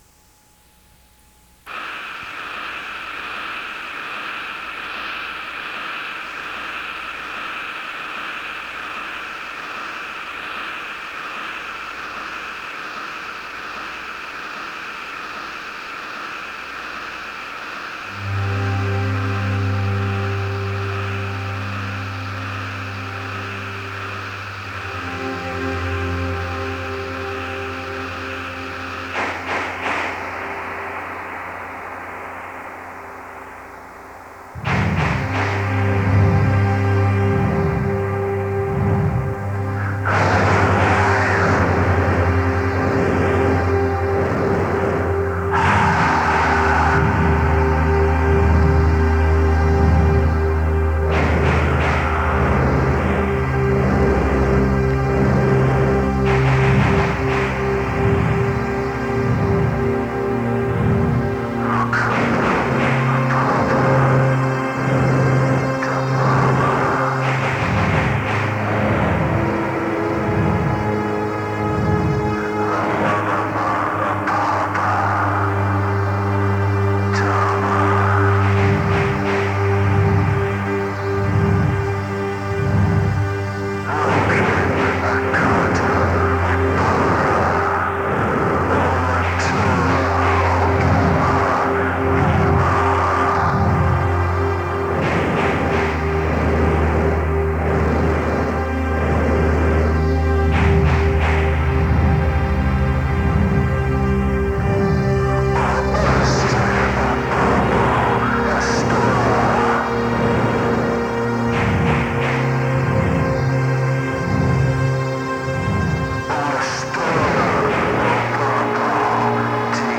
industrial noise band